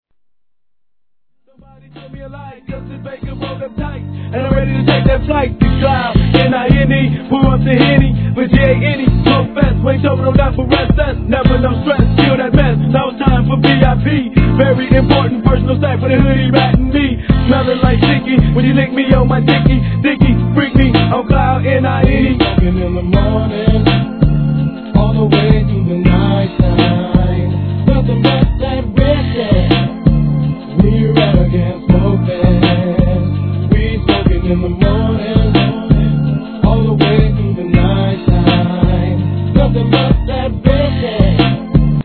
G-RAP/WEST COAST/SOUTH
脱力感あるホンワカしたトラックにフックでの和み系男性ヴォーカルが何ともたまらないGOOD TUNE!!!